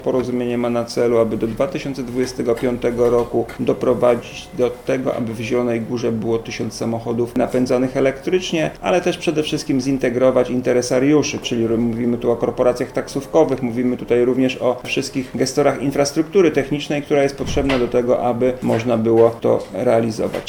Mówi Dariusz Lesicki, wiceprezydent Zielonej Góry: